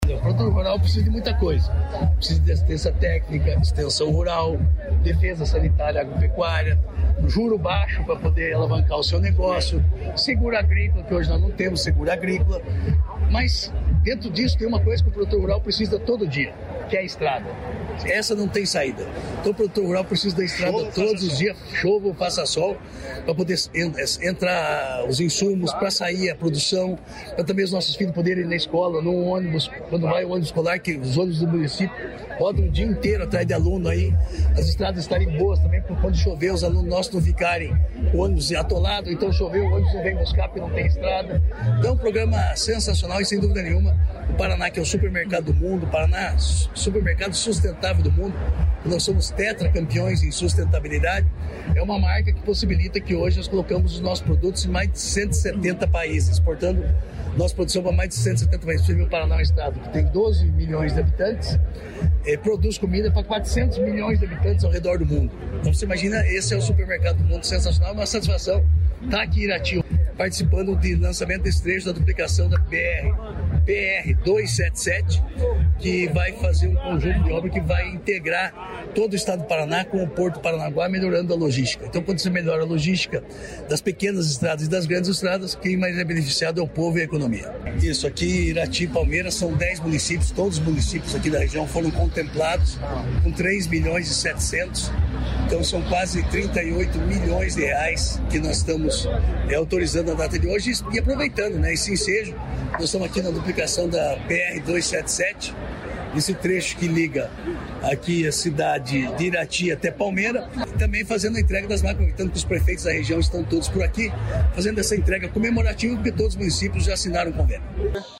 Sonora do secretário da Agricultura e Abastecimento, Márcio Nunes, sobre máquinas para estradas rurais da região Centro-Sul | Governo do Estado do Paraná